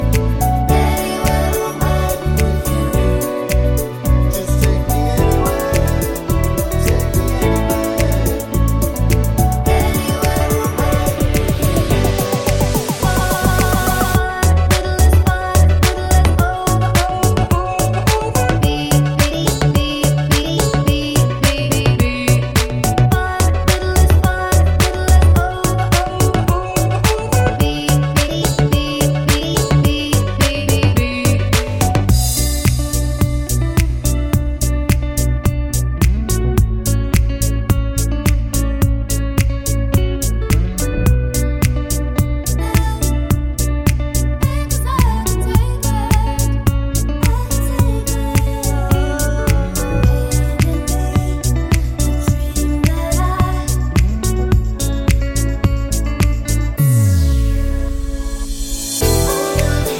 Pop (2010s)